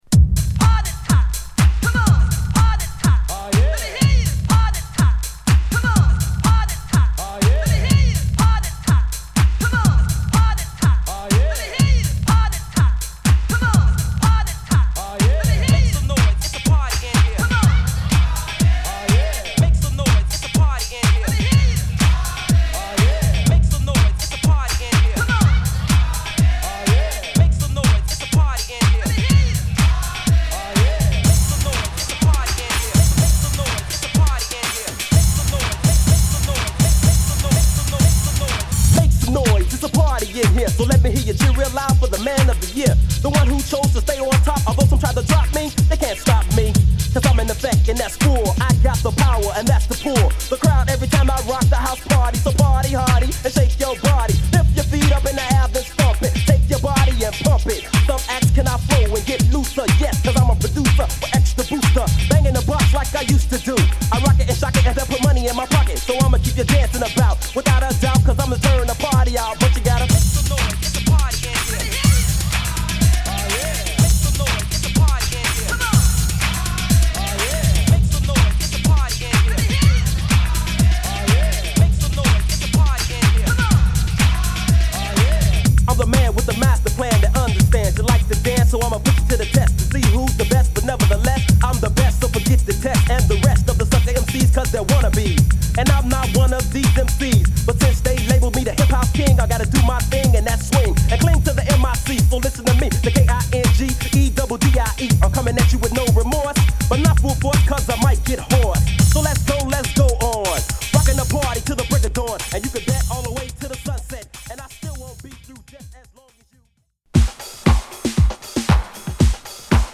Home > House Classics